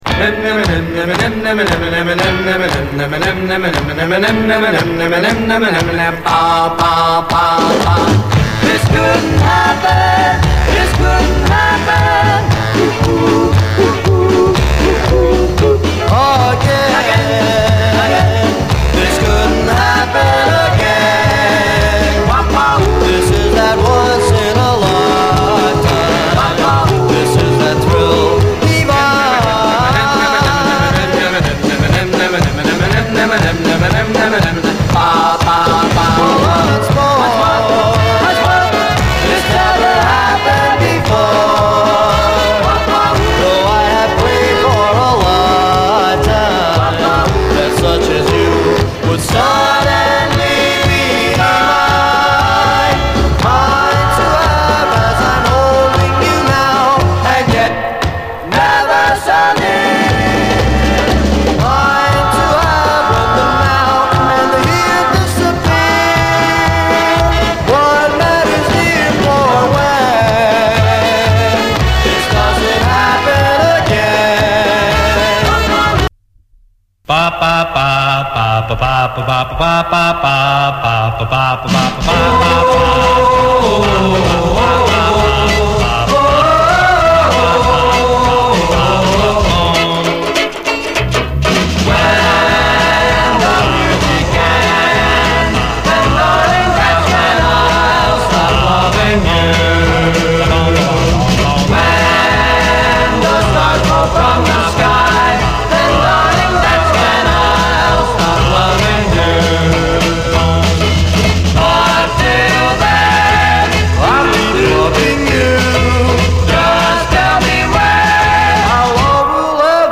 DOO WOP, OLDIES
50’Sホワイト・ドゥーワップ！
パーティー・ドゥーワップ満載！